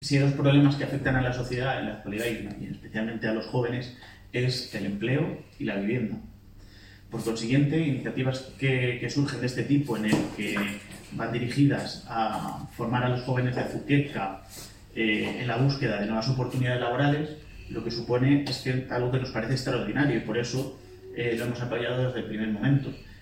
Declaraciones del alcalde Miguel Óscar Aparicio